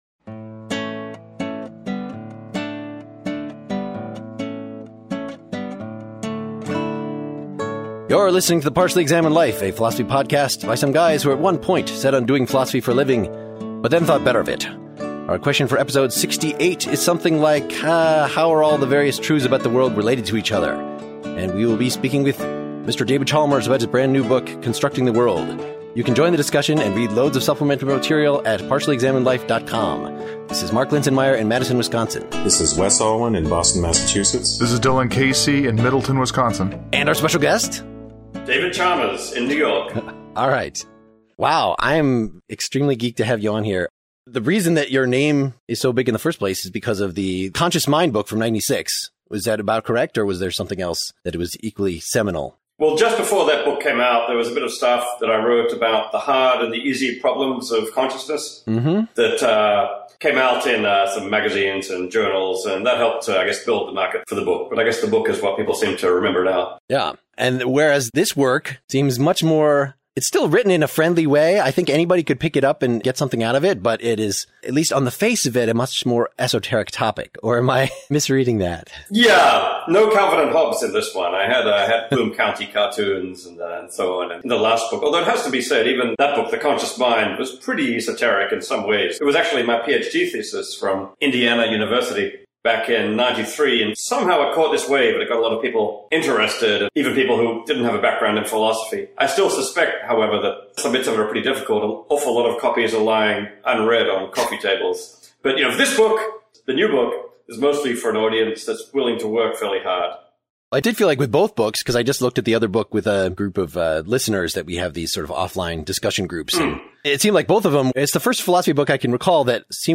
Chalmers on Scrutability (with Chalmers as guest) - Partially Examined Life